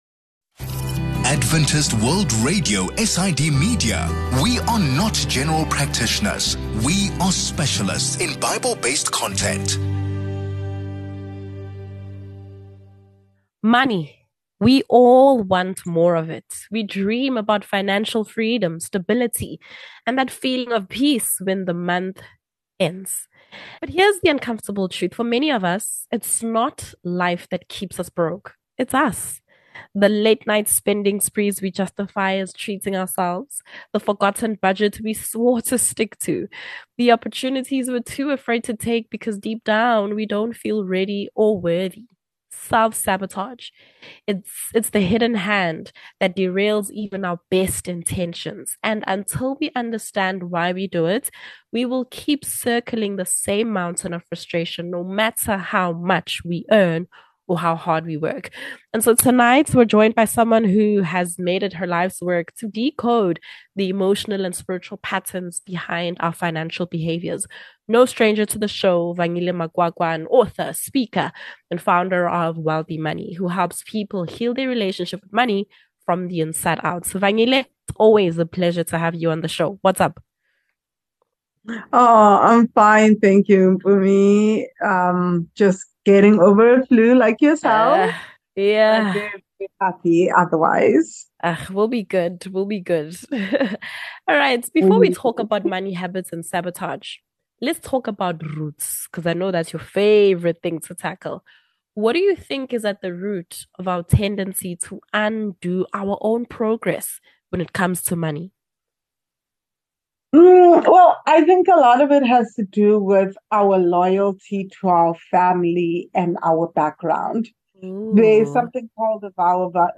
In this conversation, we’re diving into the hidden psychological forces that cause us to self-sabotage our financial future.